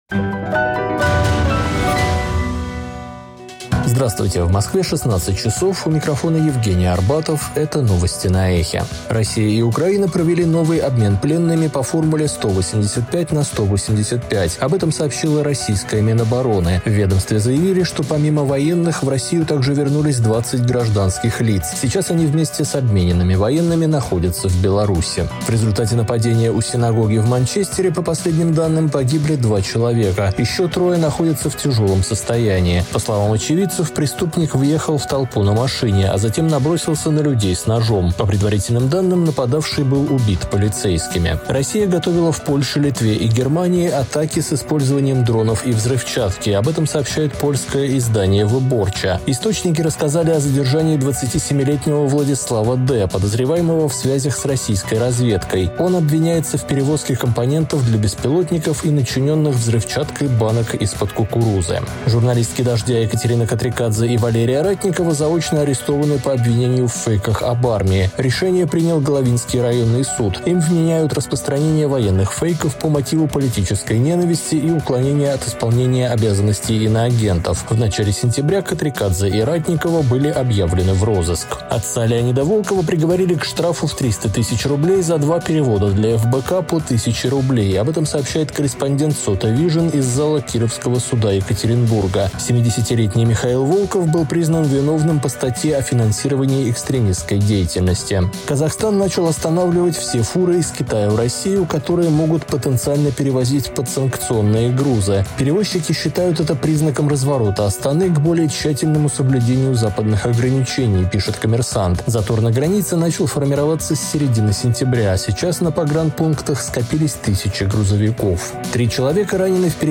Слушайте свежий выпуск новостей «Эха»
Новости 16:00